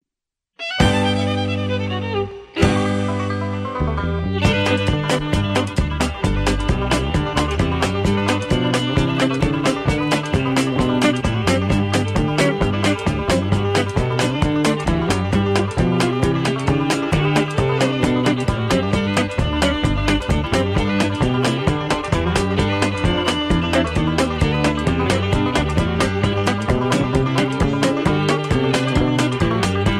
Patter (two instrumentals)